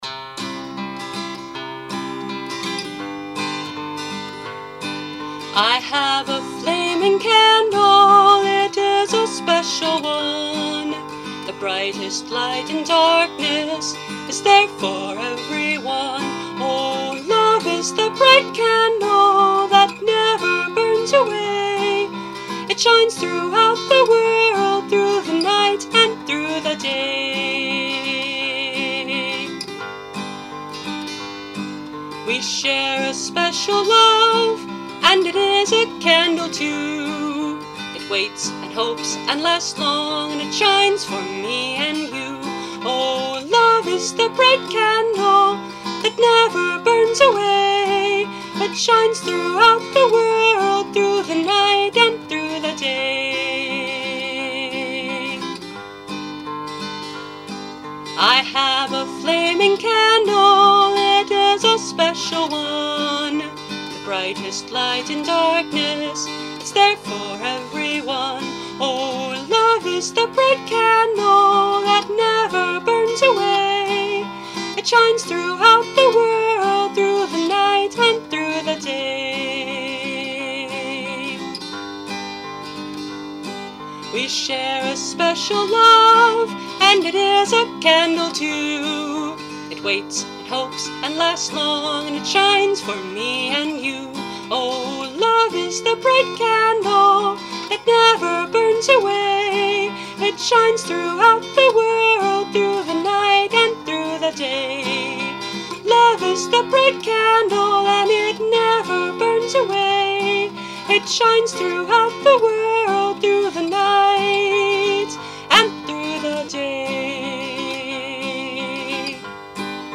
Now here it is on acoustic guitar.
(Capo 3)